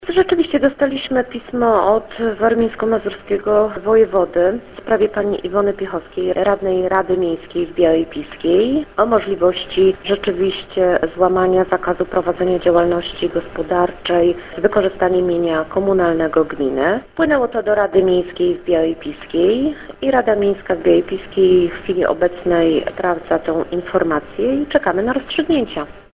Mówi Beata Sokołowska, burmistrz Białej Piskiej: